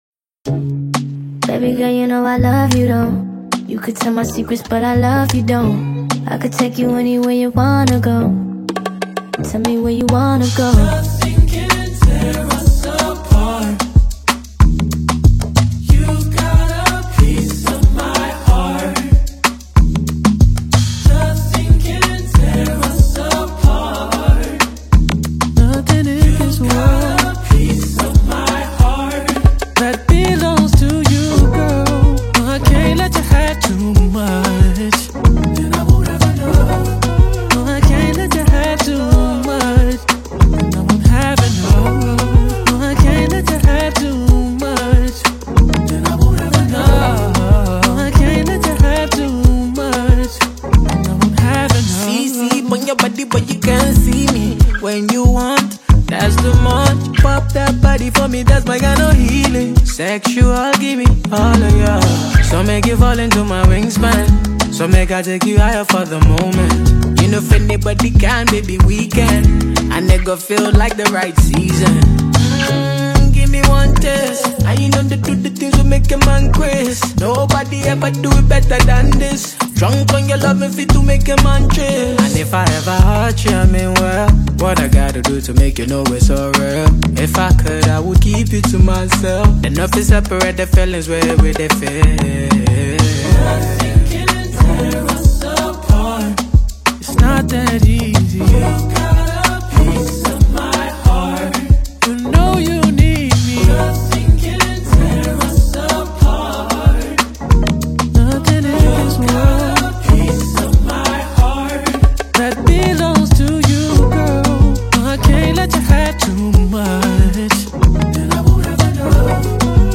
This soul-stirring single